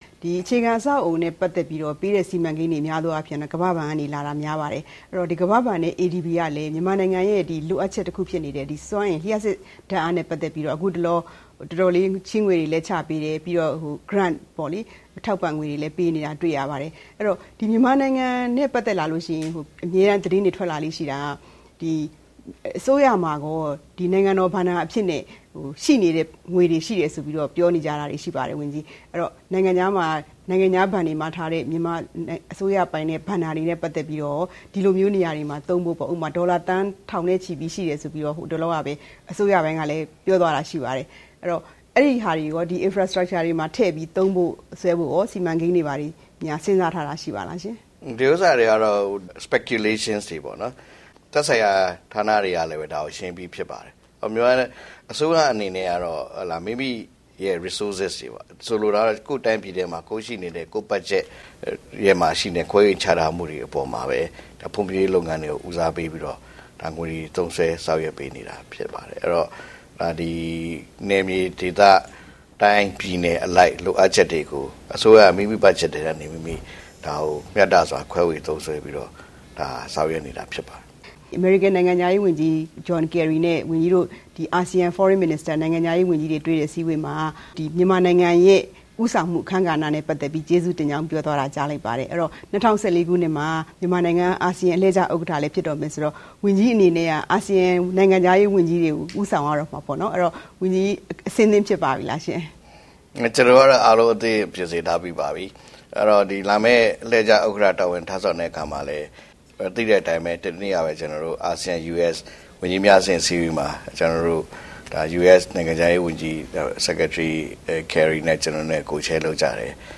နိုင်ငံခြားရေးဝန်ကြီး ဦးဝဏ္ဏမောင်လွင်နှင့် သီးသန့်တွေ့ဆုံမေးမြန်းခန်း